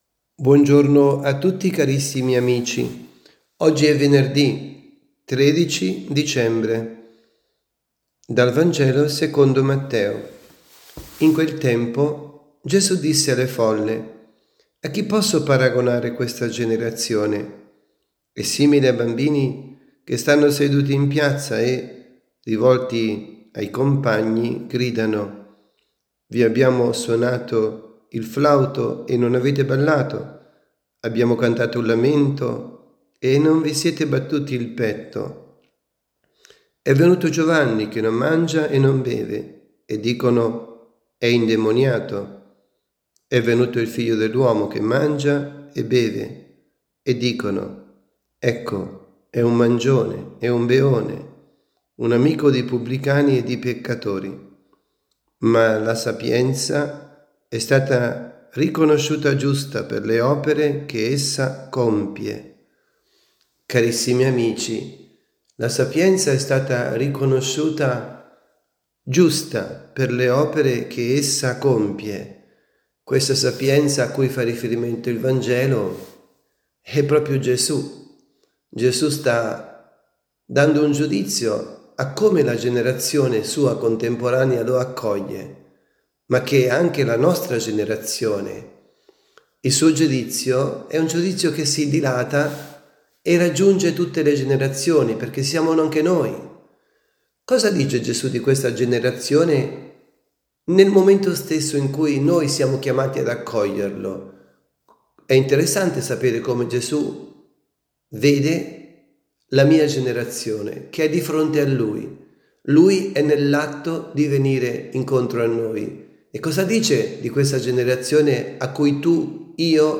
Avvento, avvisi, Catechesi, Omelie
dalla Basilica di San Nicola – Tolentino –  Vangelo del rito Romano – Matteo 11, 16-19.